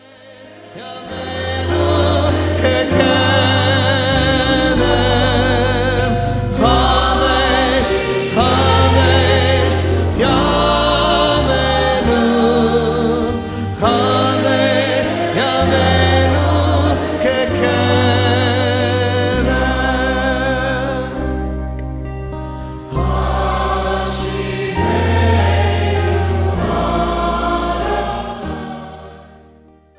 recorded with over 300 friends and fans!